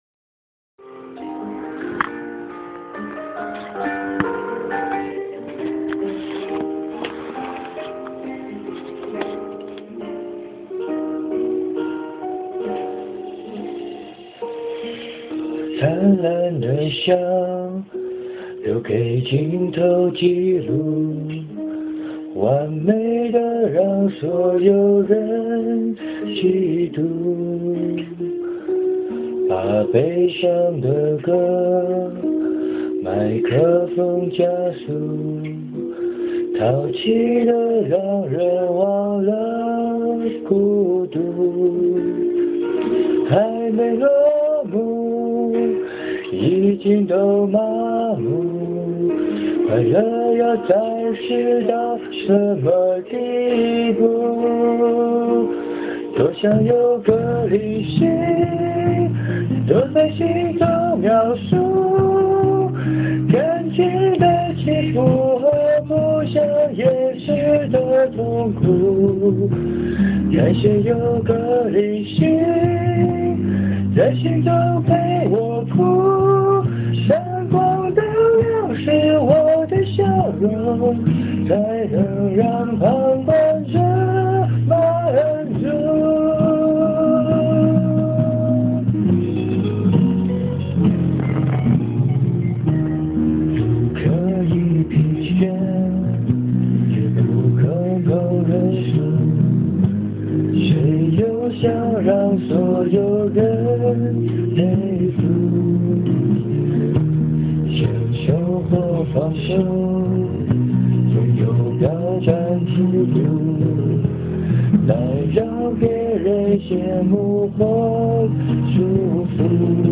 自己唱的很烂，且设备也很烂，请大家不要批评的太严厉。